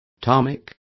Also find out how asfalto is pronounced correctly.